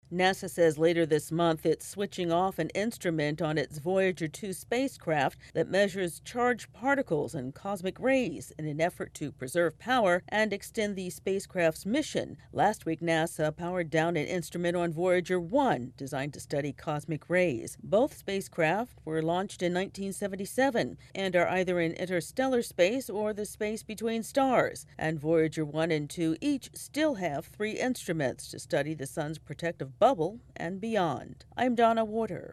NASA is trying to conserve power more than 13 billion miles from Earth. AP correspondent